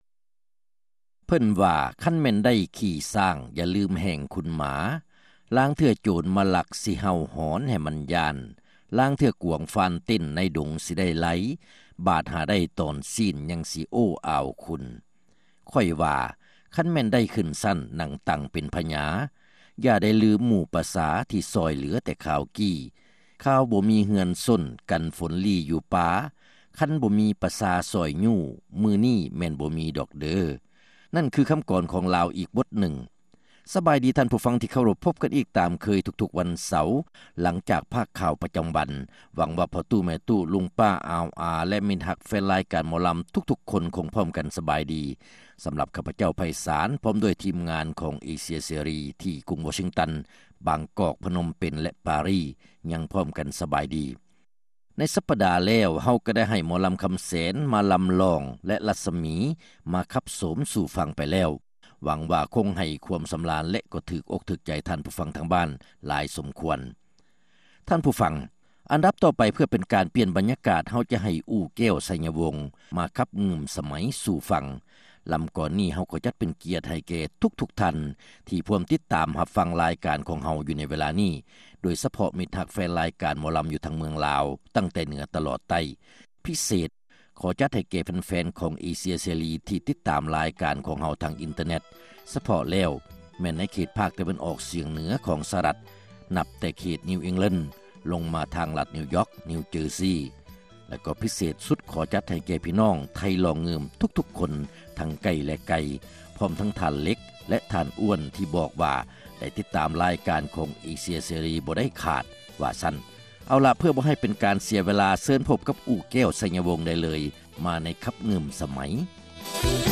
ຣາຍການໜໍລຳປະຈຳສັປະດາ